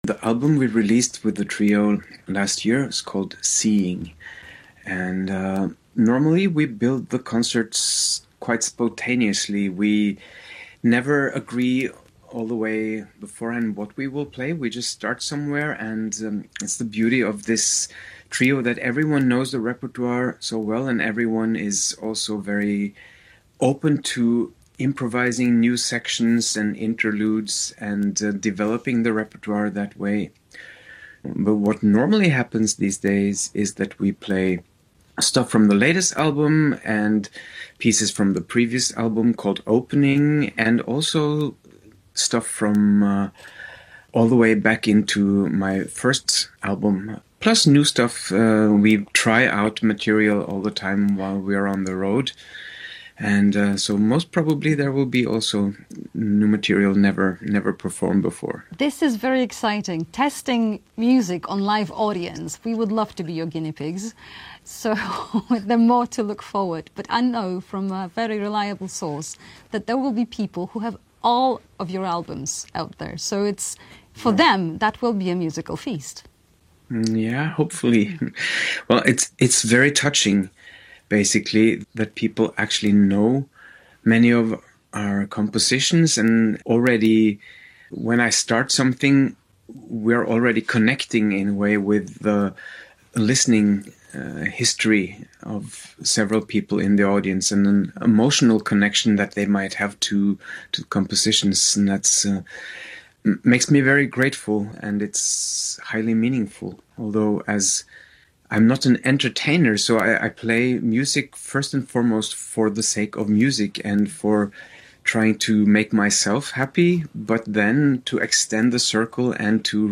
Jazz a vu: Wywiad z Tordem Gustavsenem [POSŁUCHAJ]